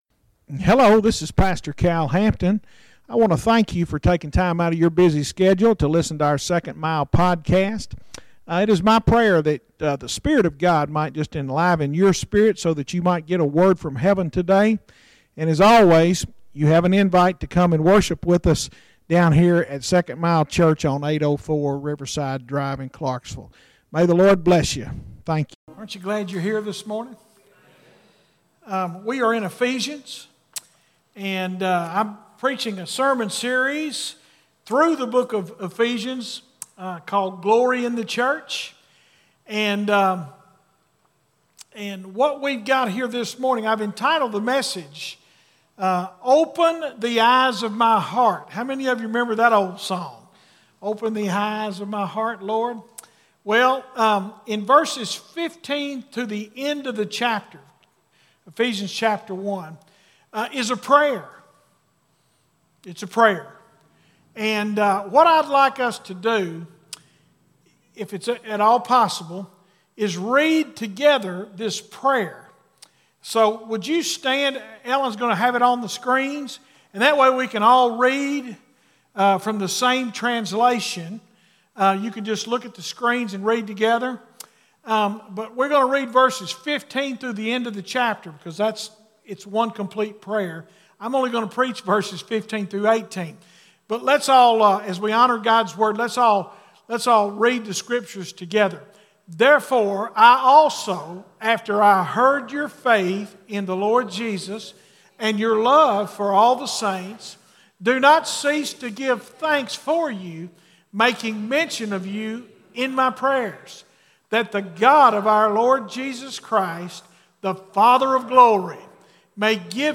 Sermons Archive - Page 173 of 311 - 2nd Mile Church